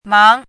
máng
注音： ㄇㄤˊ
mang2.mp3